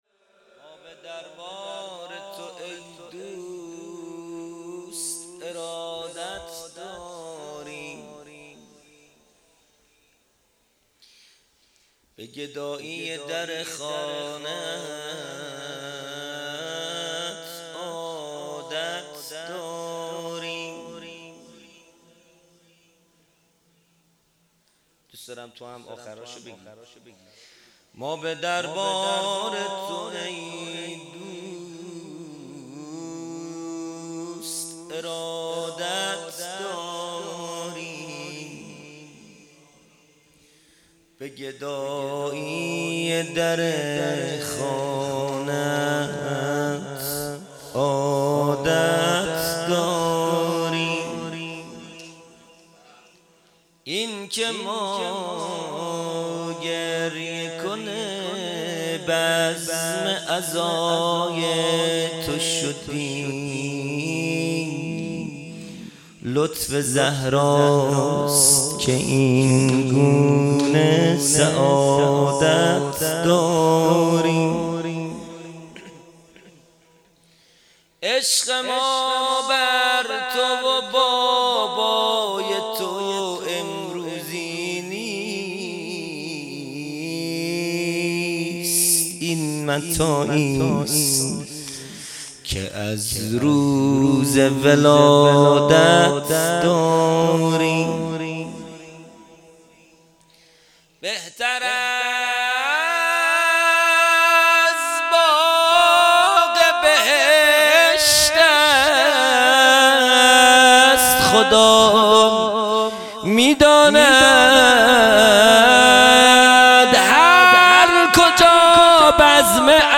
مناجات پایانی | ما به دربار تو ای دوست ارادت داریم| پنج شنبه ۱۱ شهریور ۱۴۰۰
جلسه‌ هفتگی | شهادت امام سجاد (ع) | پنج شنبه ۱۱ شهریور ۱۴۰۰